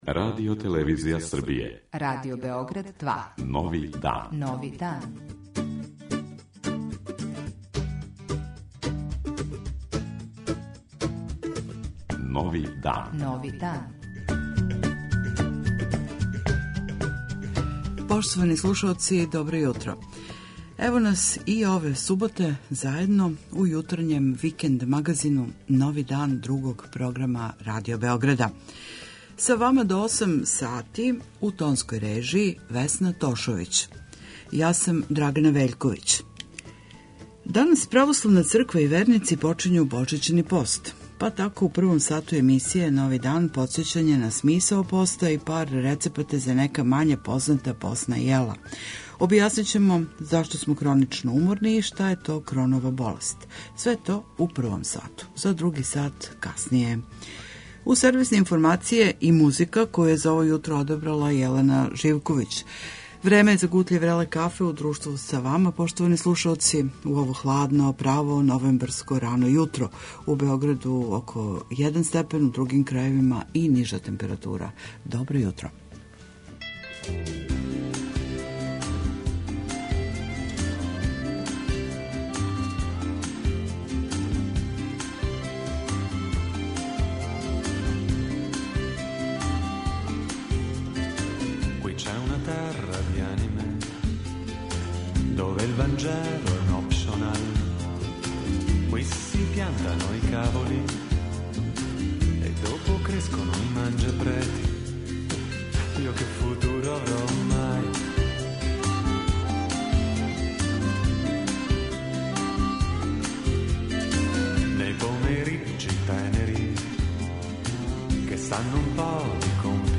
Чућете и најаве догађаја у култури неких наших градова, редовне рубрике и сервисне информације.